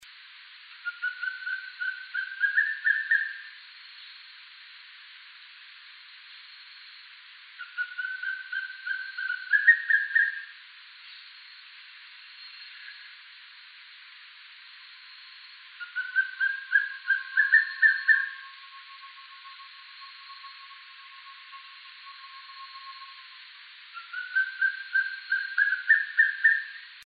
Wing-barred Piprites (Piprites chloris)
Life Stage: Adult
Province / Department: Misiones
Location or protected area: Reserva Privada y Ecolodge Surucuá
Condition: Wild
Certainty: Photographed, Recorded vocal